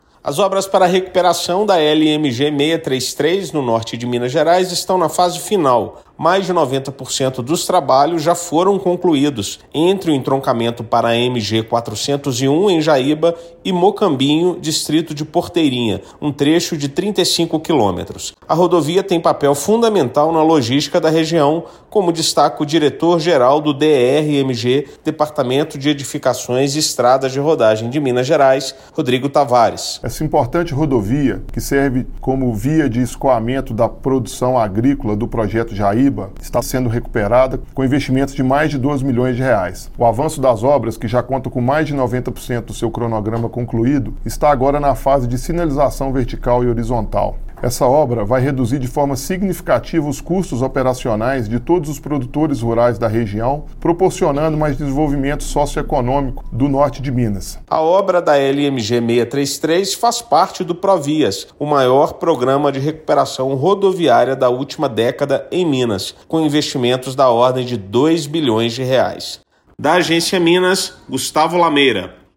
As obras de recuperação funcional da LMG-633, entre o entroncamento para a MG-401 (Jaíba) e Mocambinho, distrito de Porteirinha, na região Norte de Minas, estão na fase final. Mais de 90% dos serviços estão concluídos e as equipes de trabalho se concentram, agora, na execução da sinalização horizontal e vertical. Ouça matéria de rádio.